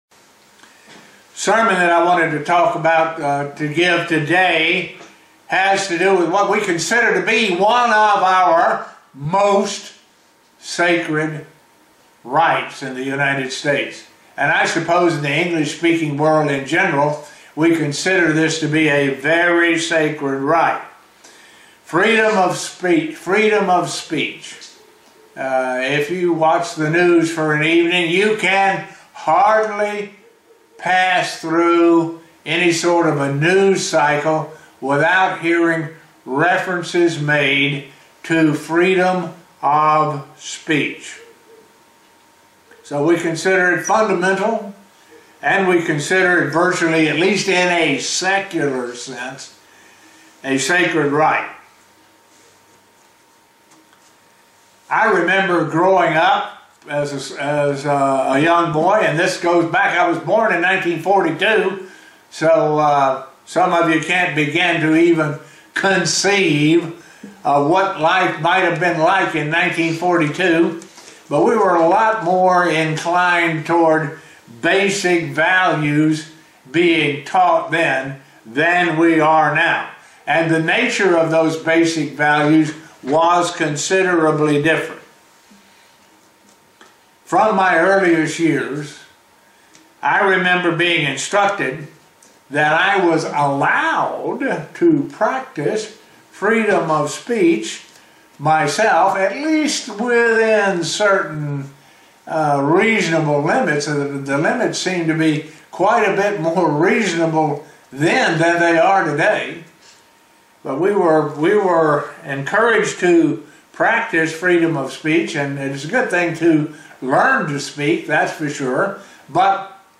Given in Buffalo, NY
Print What our fundamental free speech rights and responsibilities are as members of God's Church and how the Bible looks at freedom of speech. sermon Studying the bible?